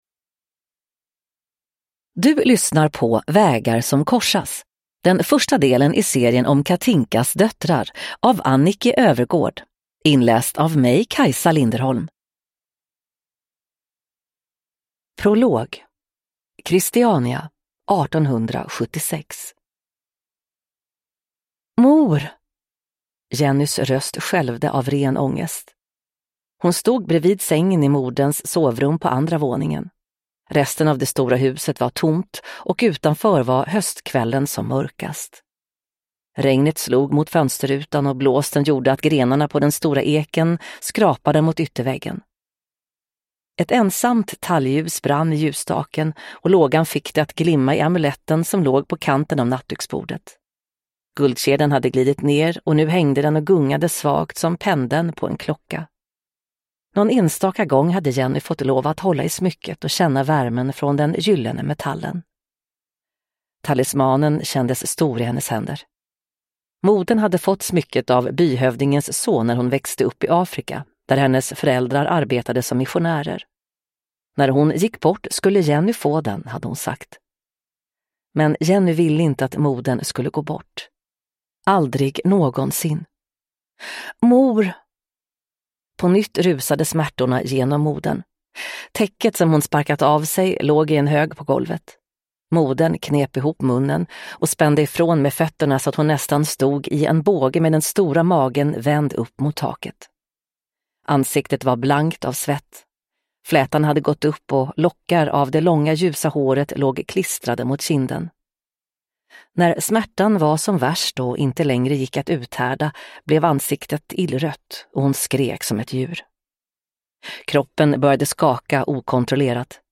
Vägar som korsas (ljudbok) av Annikki Øvergård